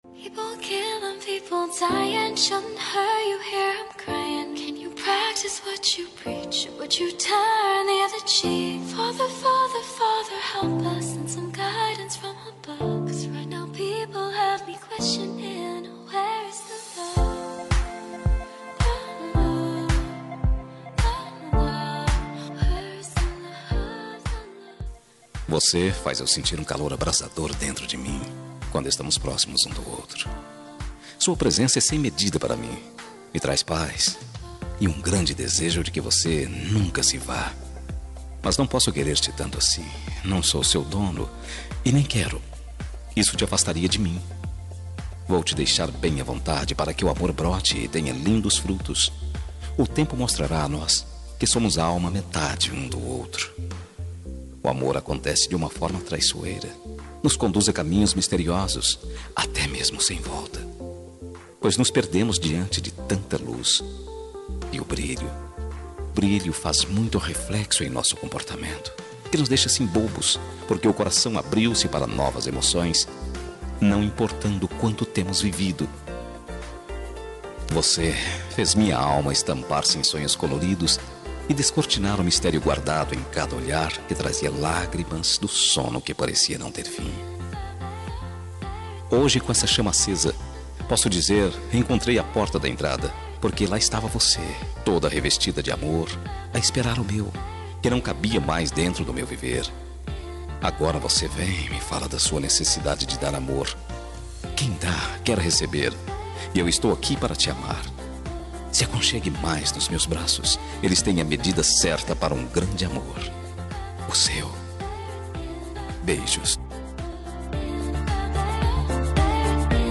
Telemensagem Romântica – Voz Masculina – Cód: 7914